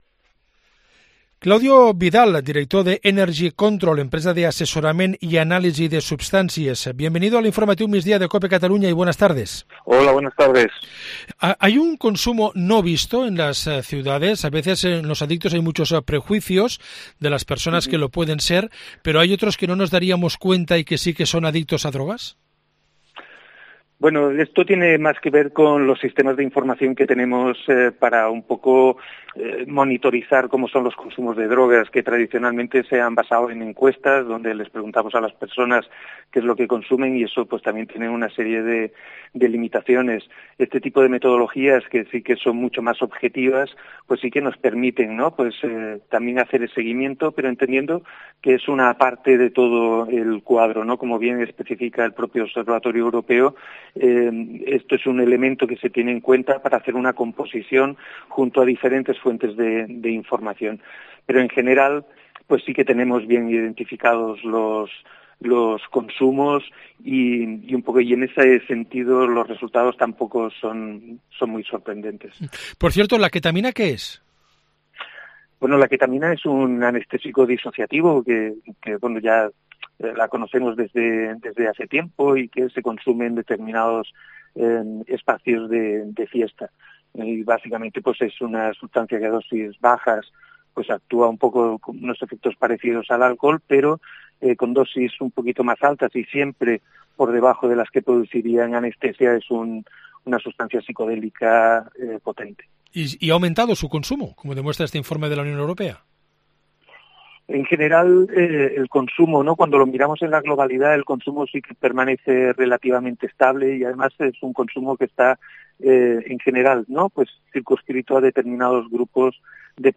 en una entrevista en el informativo de Cadena Cope Cataluña